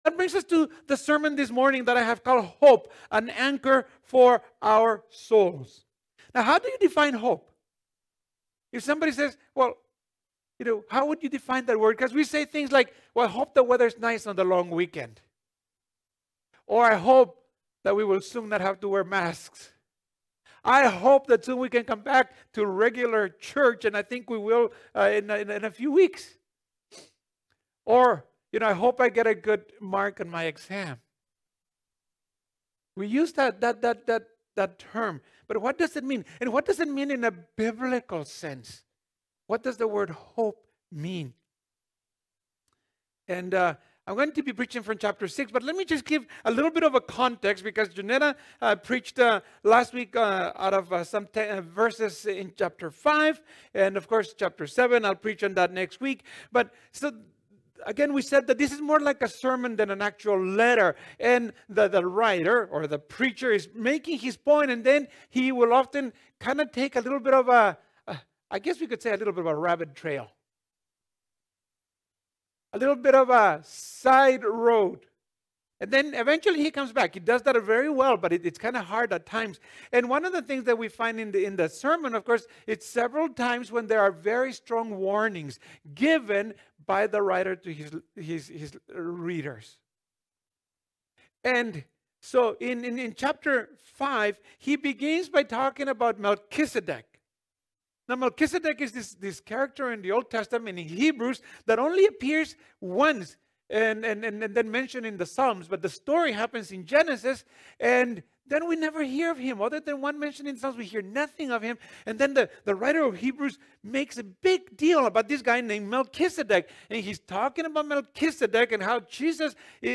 This sermon is based on Hebrews 7, particularly verses 9-12 and 18-20. This text reminds us of this hope we find in God's unchangeable character and his plan/purpose which should steady us in life's storms.